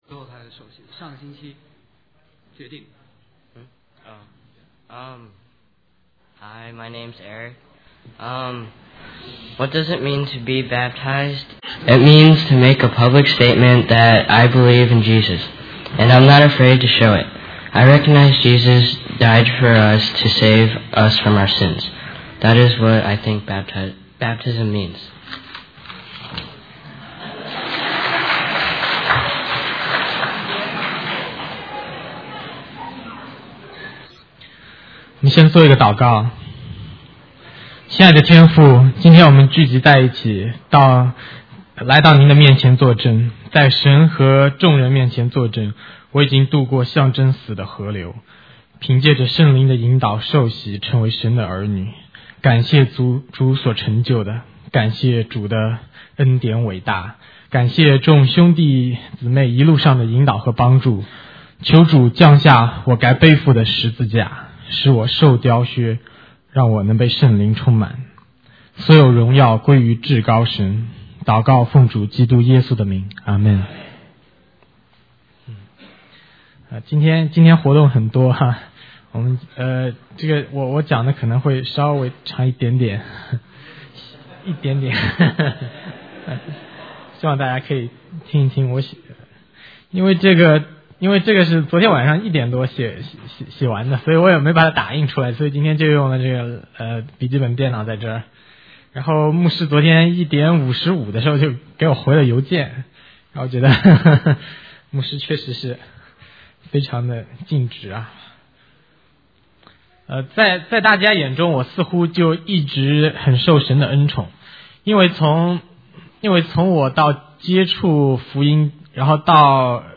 100404: 受洗见证